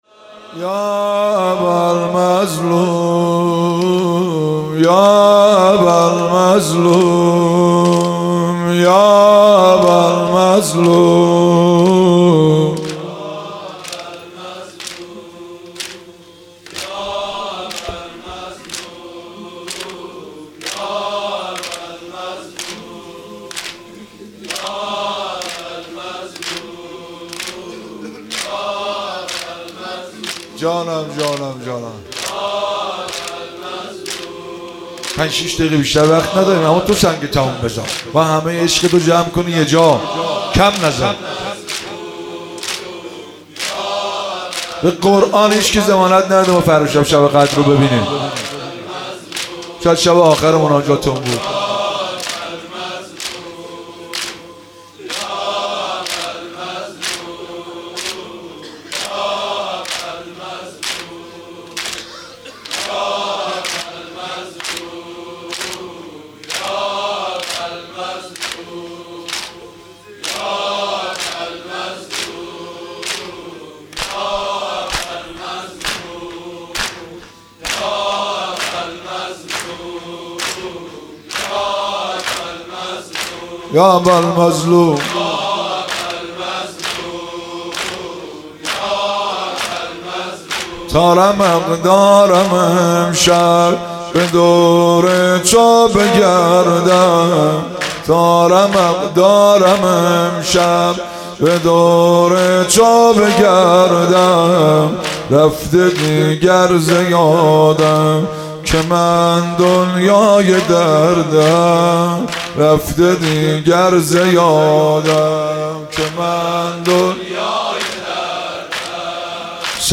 مناسبت : شب هجدهم رمضان
قالب : زمینه